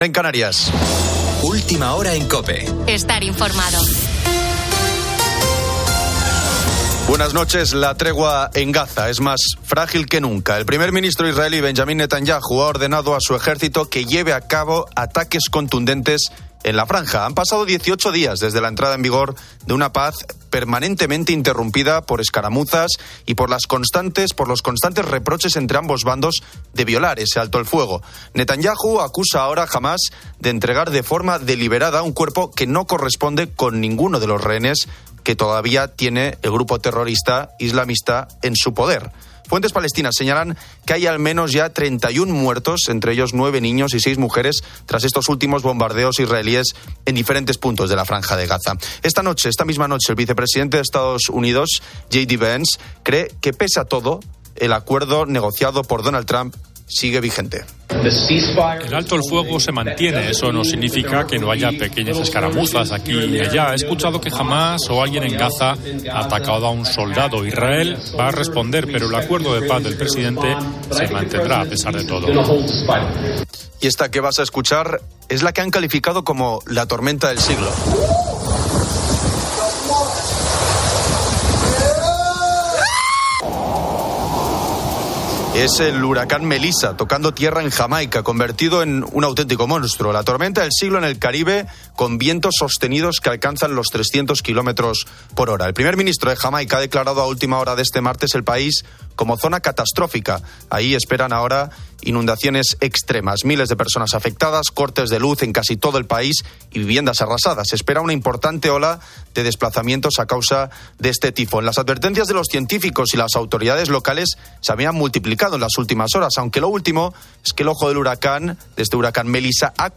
COPE acompaña en la madrugada, iluminando las historias de la noche.